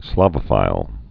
(slävə-fīl) also Slav·o·phil (-fĭl)